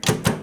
Espaciadora de una máquina de escribir
máquina de escribir
Sonidos: Oficina
Sonidos: Hogar